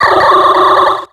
Cri de Rosabyss dans Pokémon X et Y.